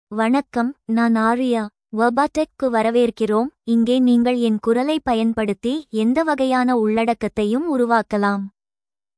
Aria — Female Tamil AI voice
Aria is a female AI voice for Tamil (India).
Voice sample
Listen to Aria's female Tamil voice.
Aria delivers clear pronunciation with authentic India Tamil intonation, making your content sound professionally produced.